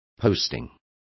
Complete with pronunciation of the translation of postings.